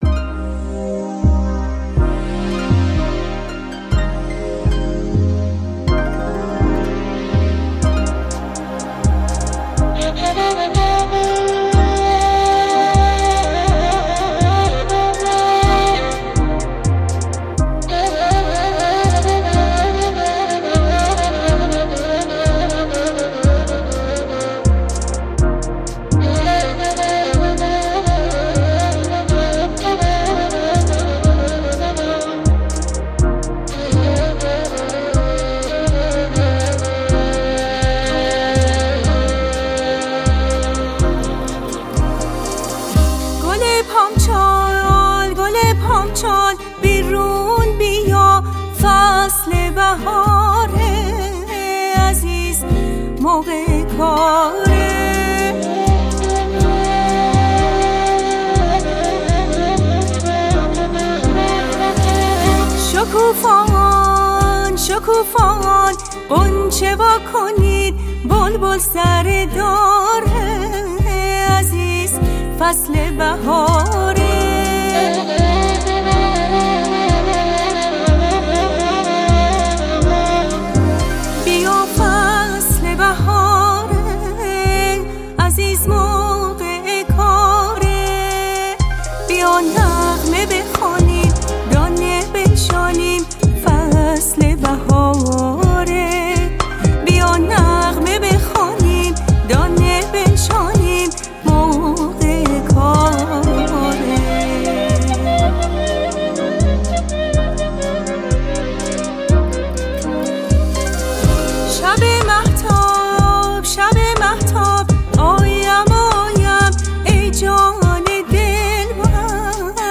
آهنگ محلی